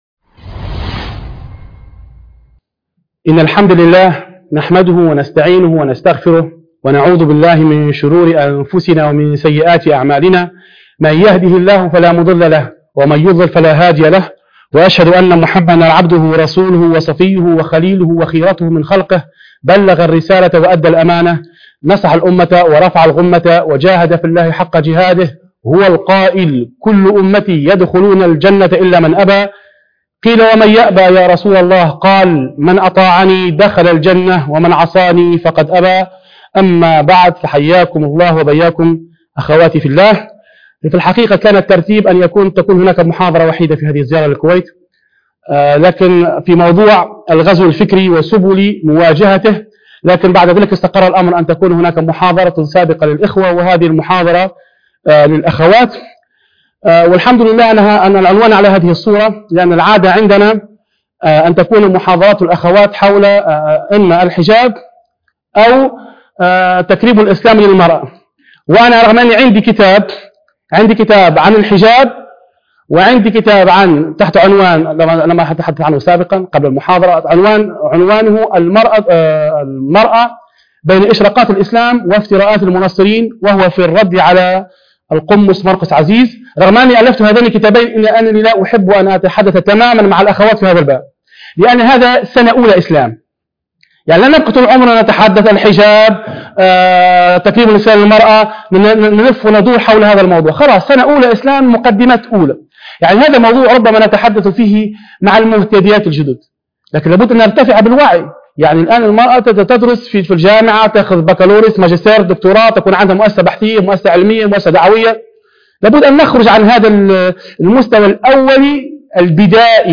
محاضرة الغزو الفكري وسبل مواجهته